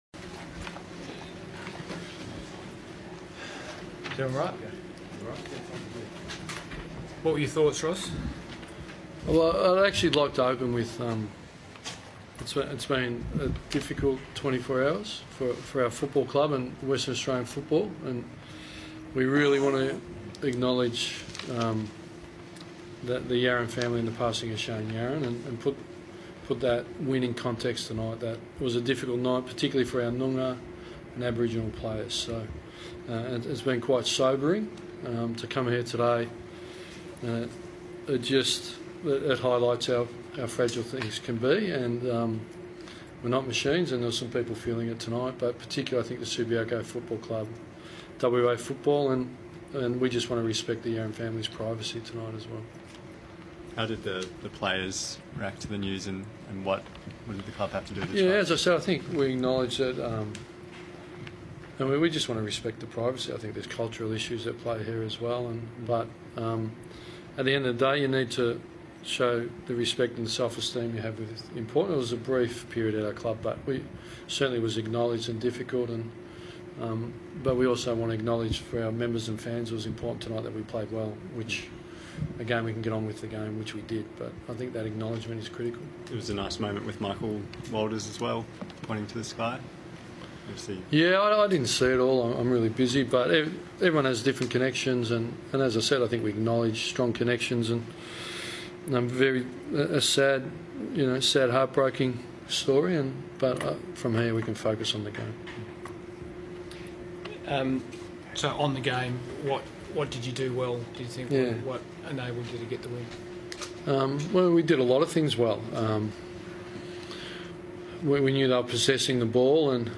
Watch Ross Lyon's press conference after round five's match against The Bulldogs